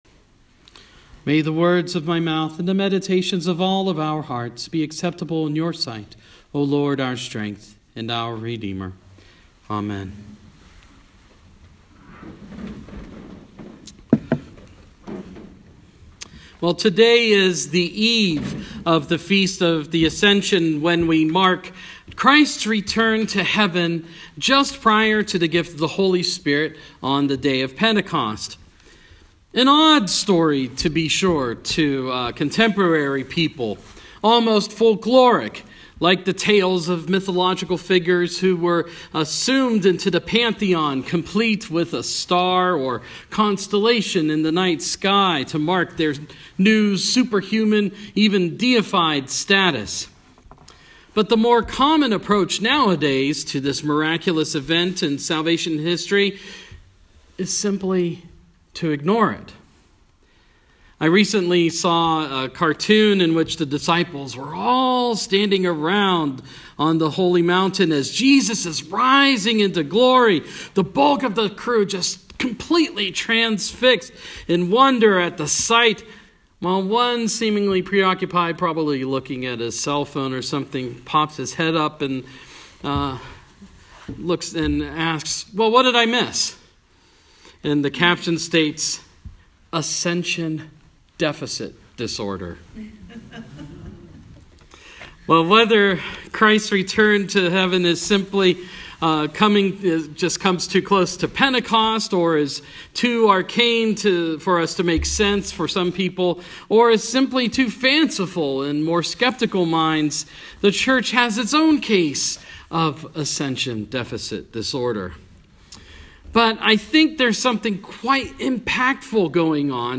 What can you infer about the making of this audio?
Tonight we celebrated the Ascension, on the eve of the feast.